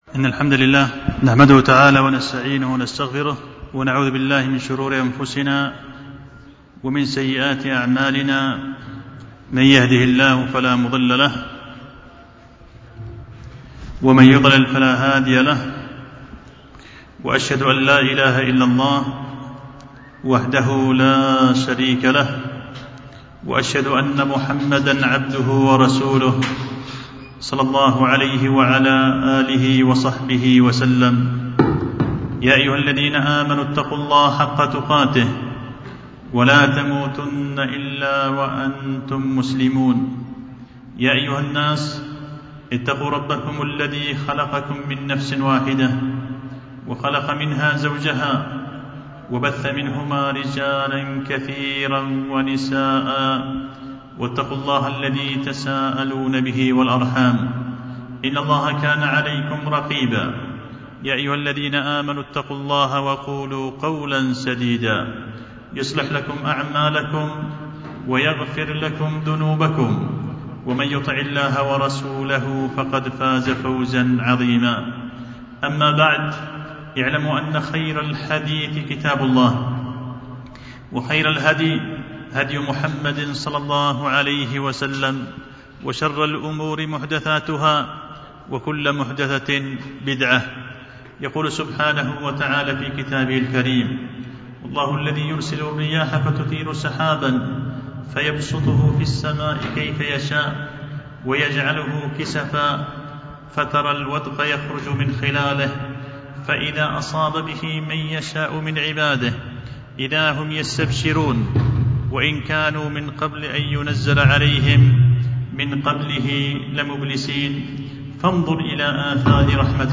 خطبة جمعة بعنوان شكر الرحيم الغفار على نعمة الأمطار